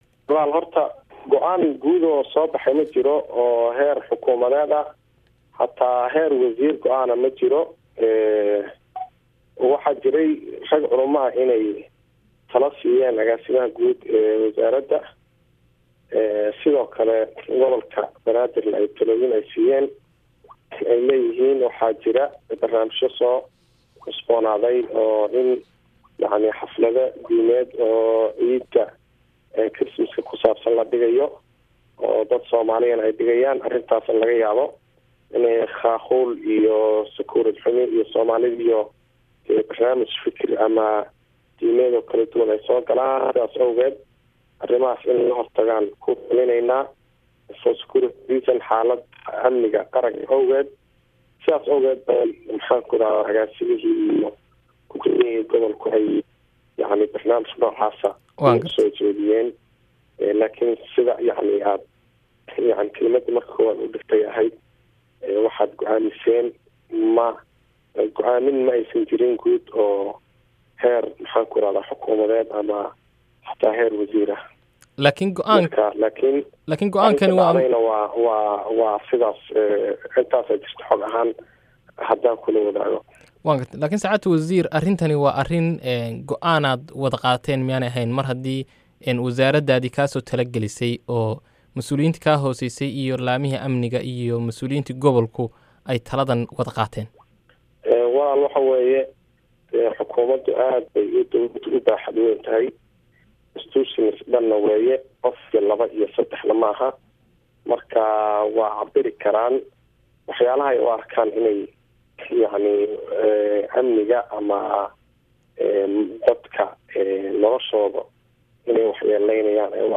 Wareysi: Wasiirka diinta iyo awqaafta Somalia